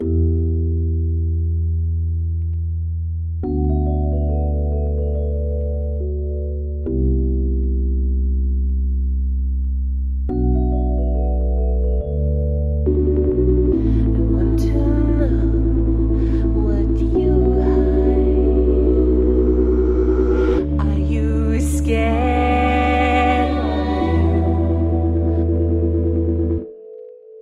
罗德斯
描述：我用fl studio VST、Sytrus制作的Rhodes。
Tag: 148 bpm Hip Hop Loops Piano Loops 2.18 MB wav Key : Unknown